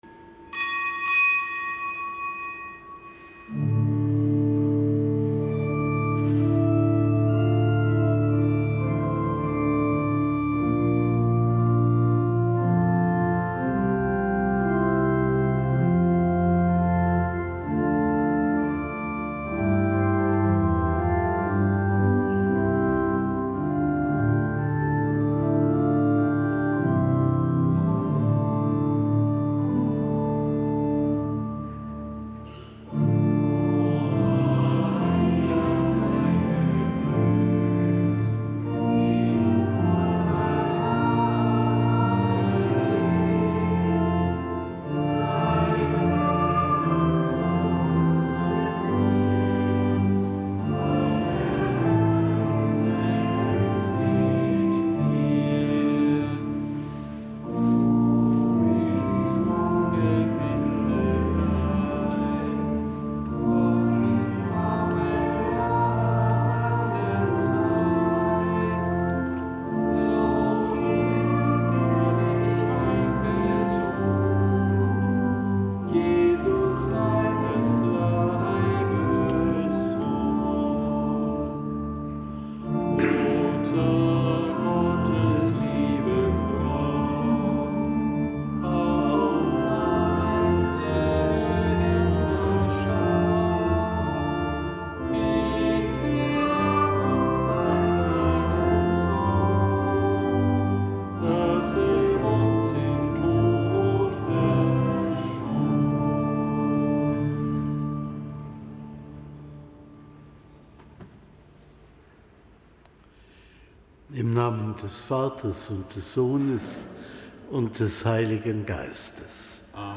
Kapitelsmesse aus dem Kölner Dom am Samstag der einunddreißigsten Woche im Jahreskreis. An einem Marien-Samstag und nicht gebotenen Gedenktag des Seligen Johannes Duns Scotus, Ordenspriester (DK).
Zelebrant: Weihbischof Rolf Steinhäuser.